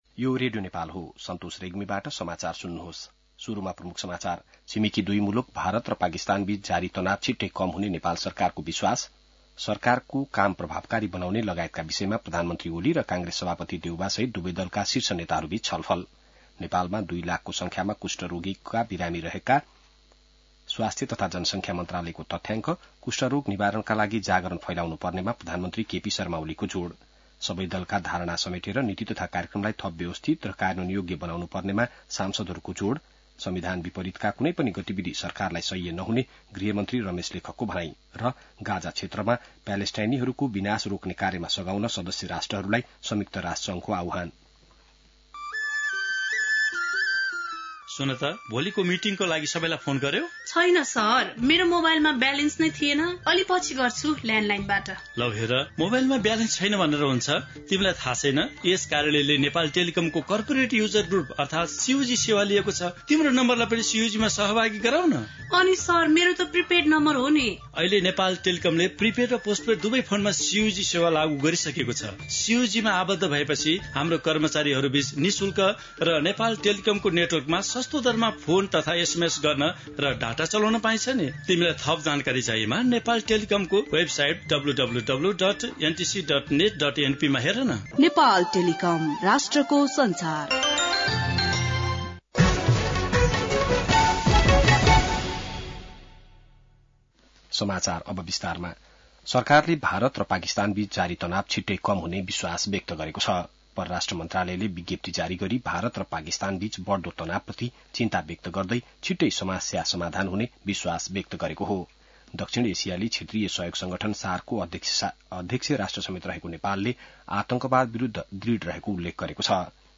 बिहान ७ बजेको नेपाली समाचार : २६ वैशाख , २०८२